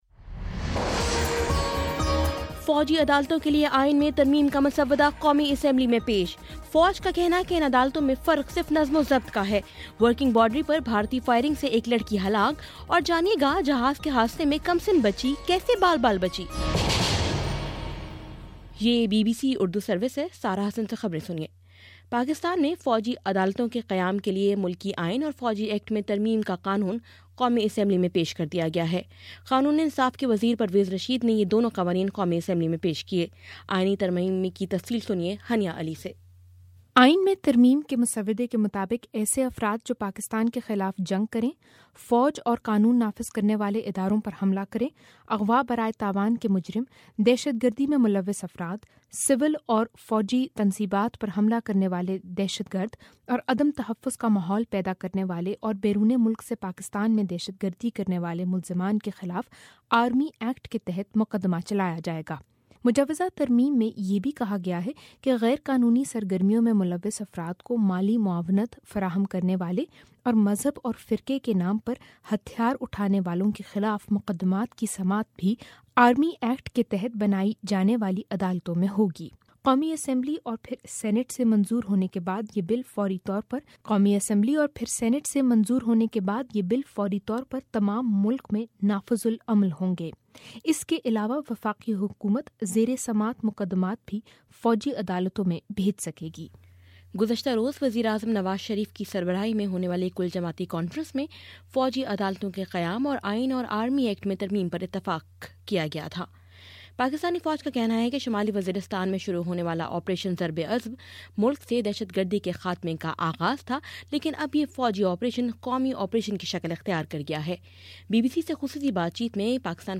جنوری 03: شام چھ بجے کا نیوز بُلیٹن